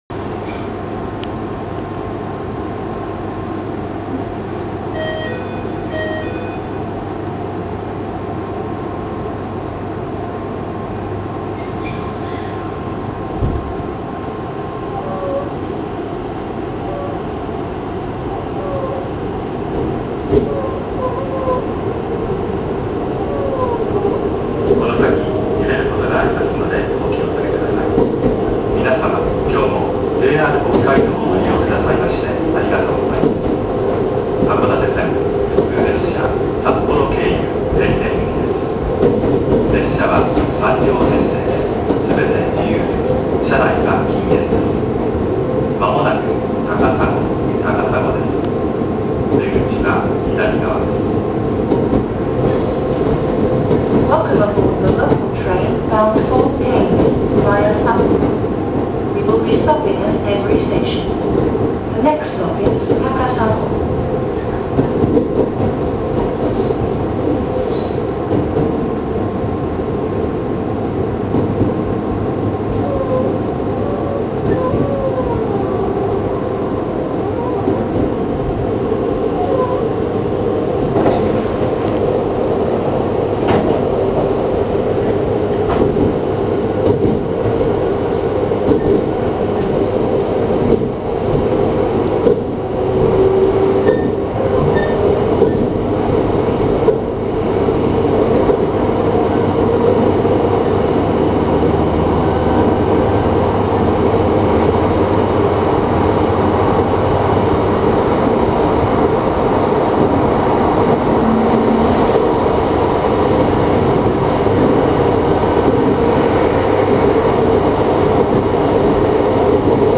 ・733系0番台走行音
【函館線】江別→高砂（2分56秒：959KB）
江別始発の列車での走行音となります。
北海道の車両全般に言える事なのですが、防寒の為か床が厚いようで、モーター音は殆ど聞こえません。